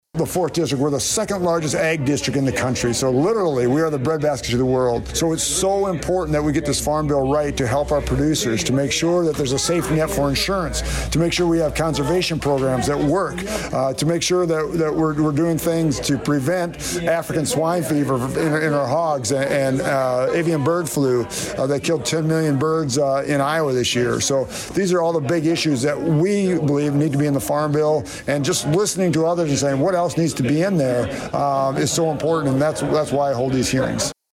Humboldt, IA – Iowa Congressman Randy Feenstra held a town hall at KC Nielsen midday Wednesday to talk about the 2023 Farm Bill among other issues. Feenstra talks about his reasoning for wanting to hear from his constituents in Humboldt on Wednesday.